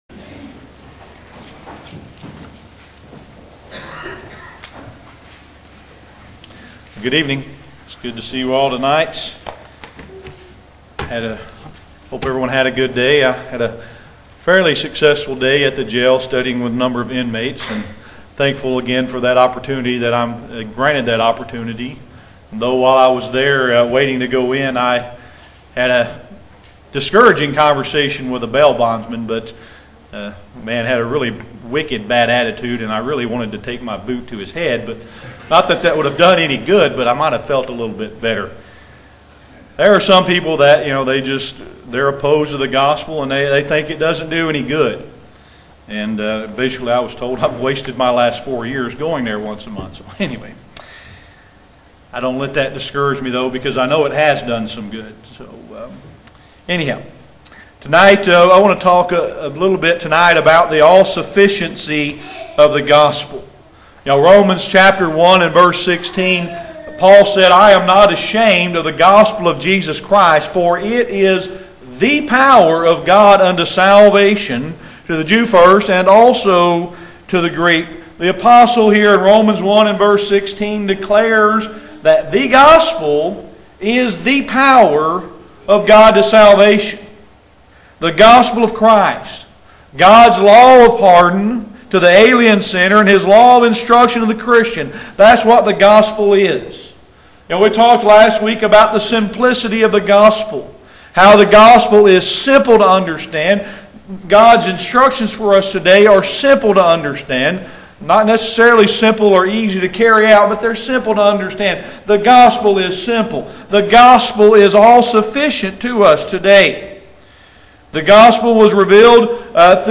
Discover why the gospel is perfect, powerful, and why it's the only way to bring about salvation. Explore the scriptures through this sermon to find out more.